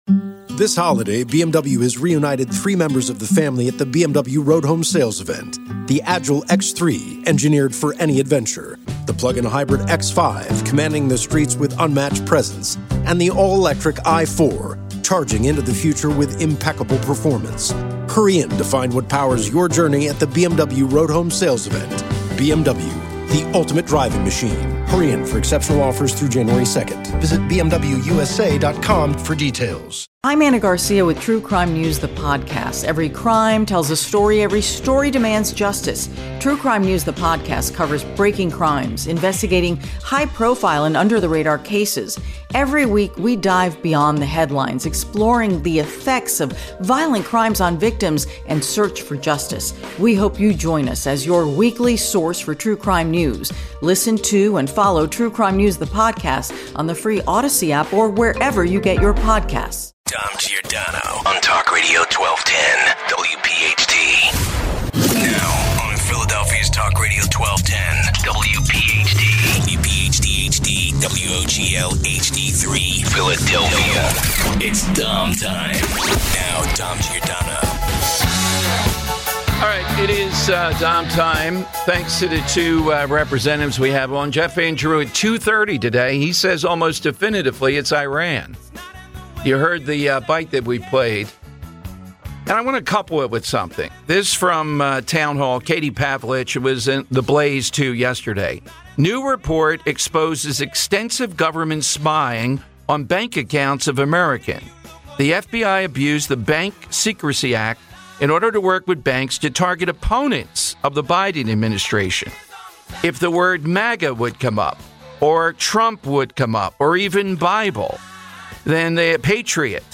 110 - Continuing with the drone talk as we take your calls. Some more side question talk. 120 - Capitol police stopped an attacker from injuring Nancy Mace over her transgender bathroom stance.